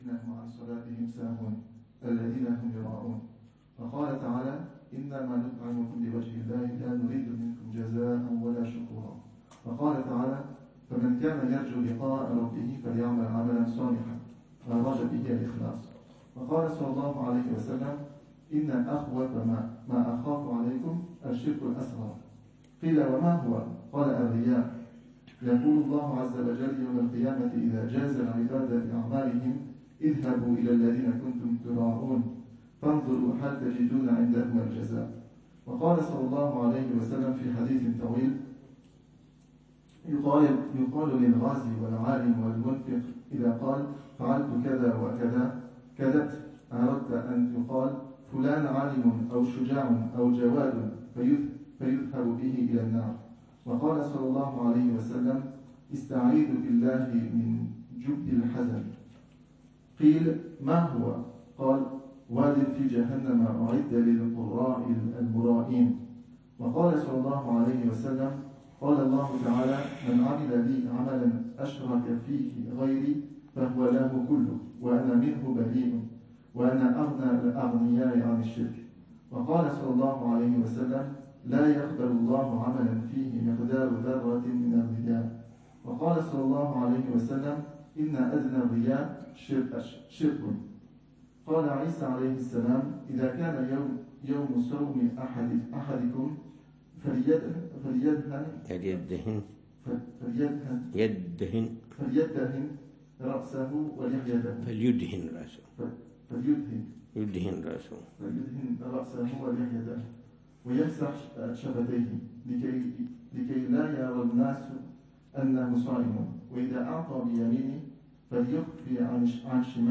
الدرس ال29 في شرح الأربعين في أصول الدين: الرياء، ودرجاته، وأبوابه الخفيّة إلى الأعمال